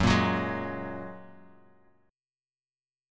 F#add9 chord